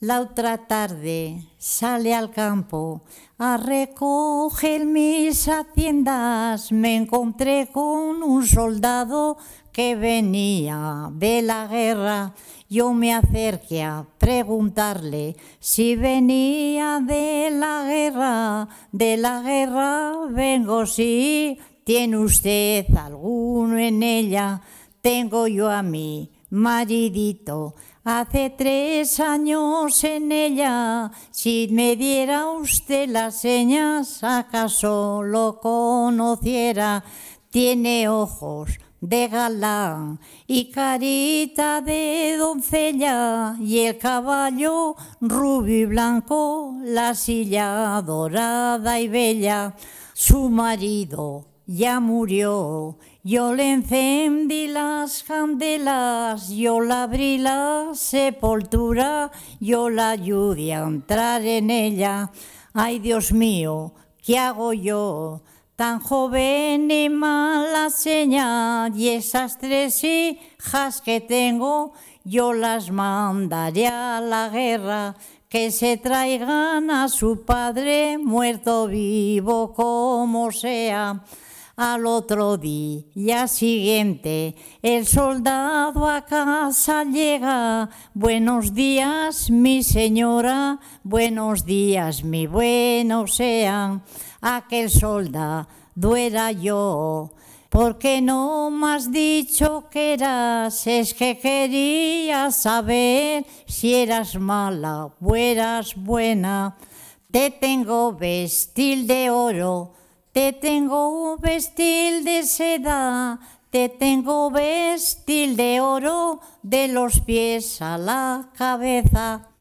Clasificación: Romancero
Localidad: Calahorra
Cuando en la entrevista se arrancaba a cantar lo hacía con las viejas cadencias del romancero y es un verdadero deleite el escucharla.